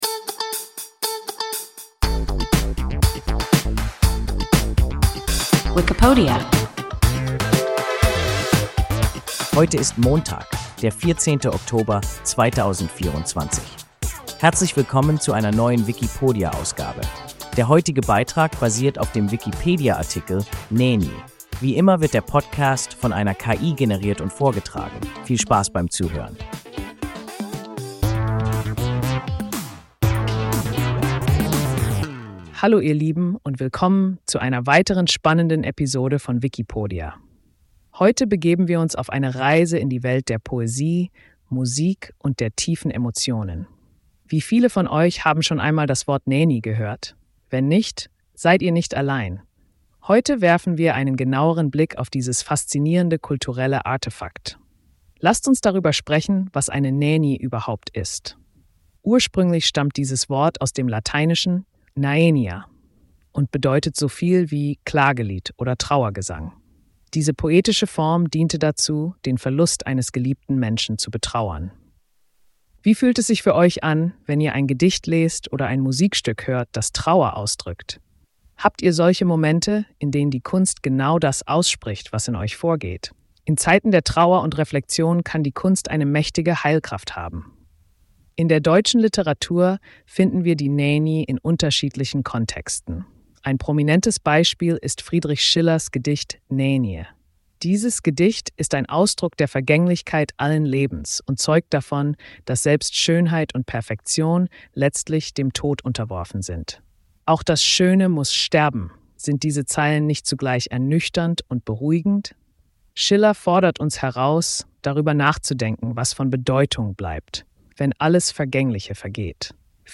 Nänie – WIKIPODIA – ein KI Podcast